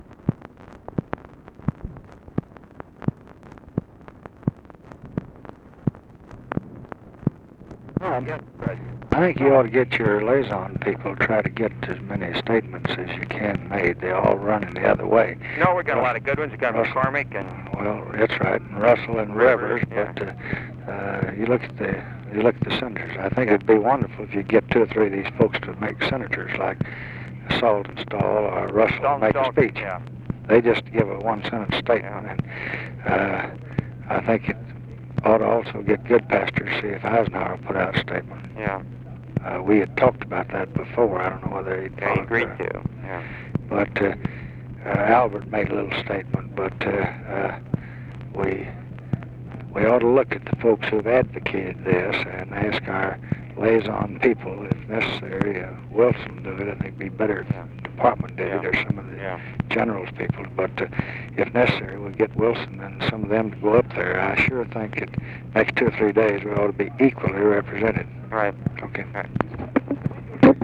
Conversation with ROBERT MCNAMARA, June 29, 1966
Secret White House Tapes